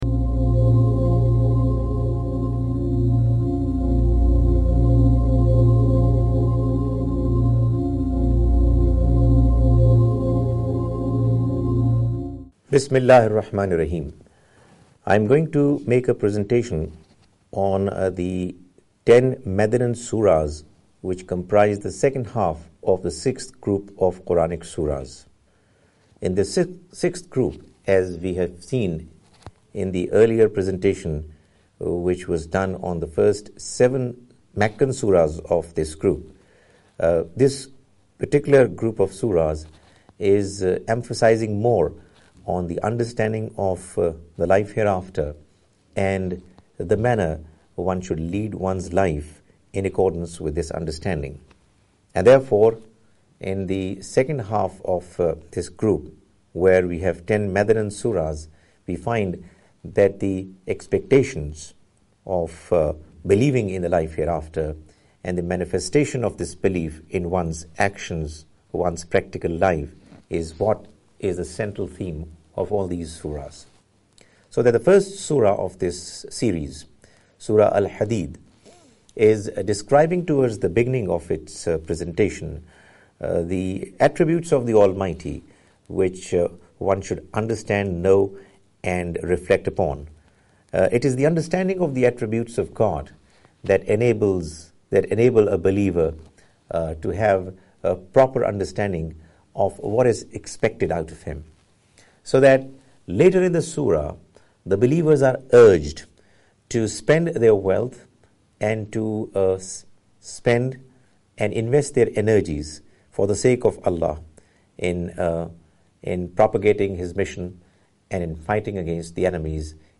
A lecture series